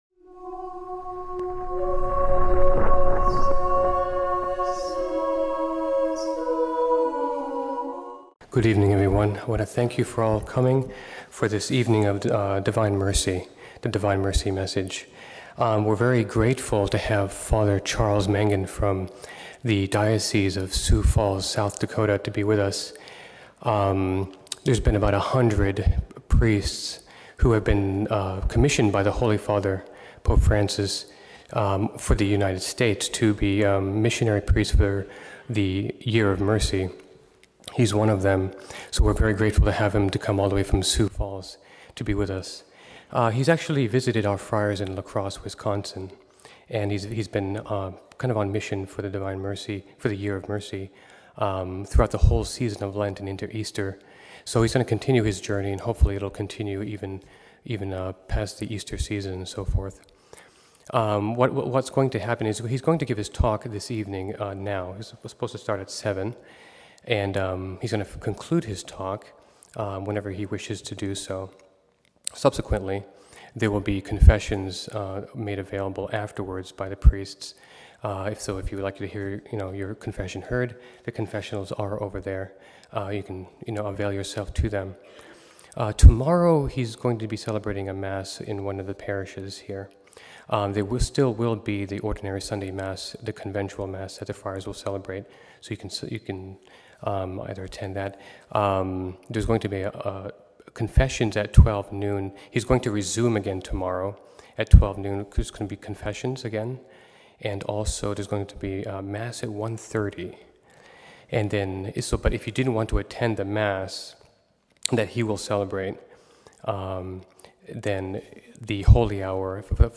conference